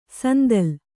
♪ sandal